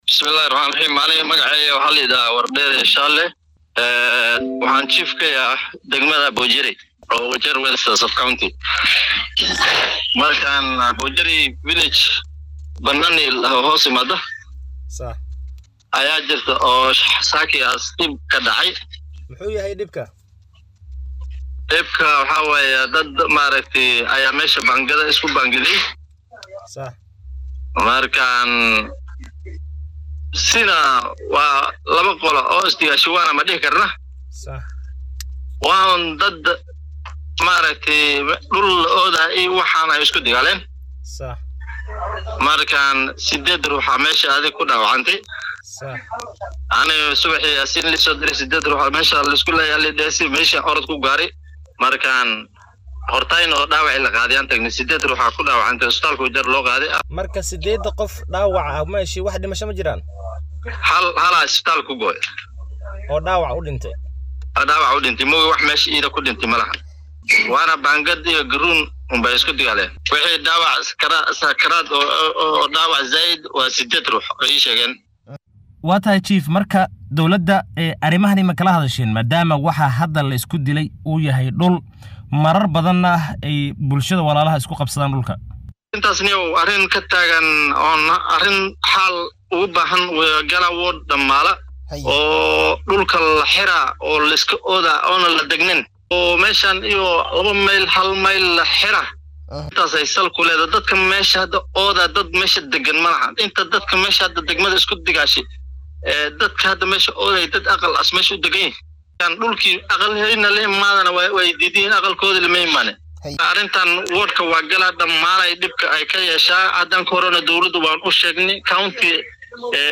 Hal qof ayaa la xaqiijiyay inuu dhintay halka ugu yaraan 8 qof haatan laga daweynaya dhaawacyo soo gaaray ka dib markii uu isqabqabsi dhul la xiriiro uu ka dhacay mid ka mid ah tuulooyinka Galbeedka ismaamulka Wajeer. Guddoomiye xaafadeedka goobta dhacdadan laga soo tabiyay ayaa u warramay